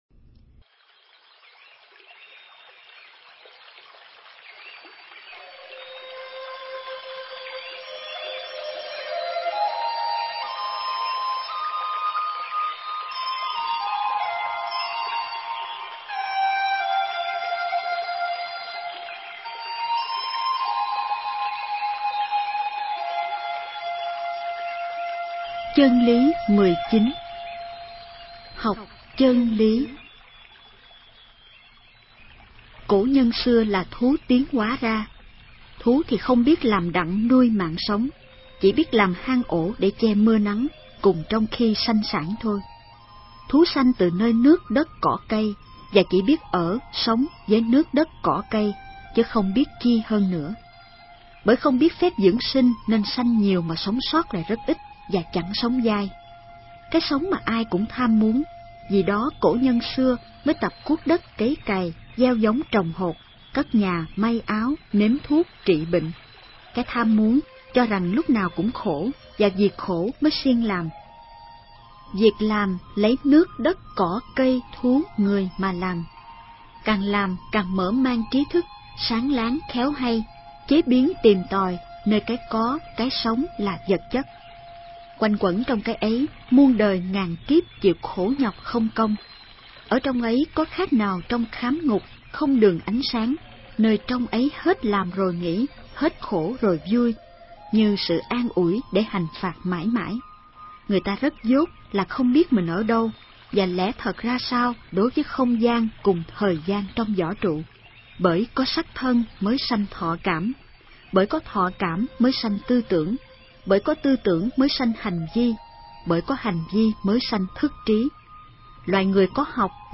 Nghe sách nói chương 19. Học Chơn Lý